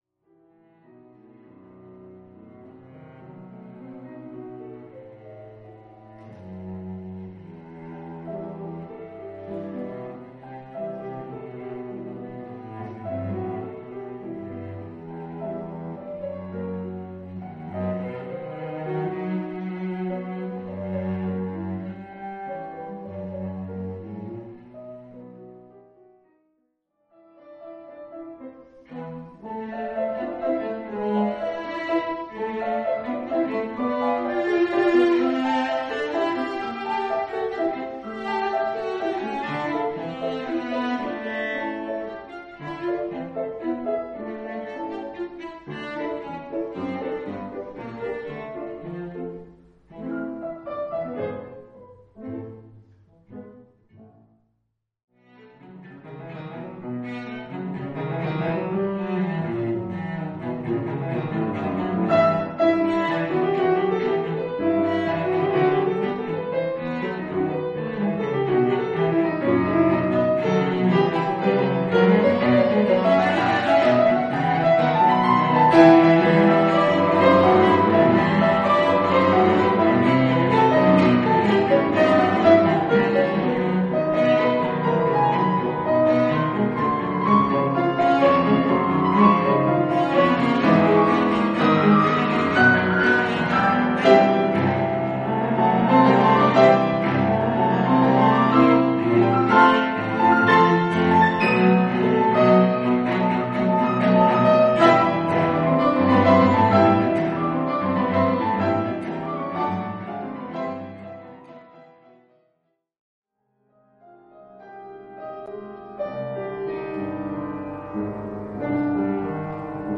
Extraits musicaux